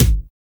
TONAL KICK.wav